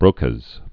(brōkəz)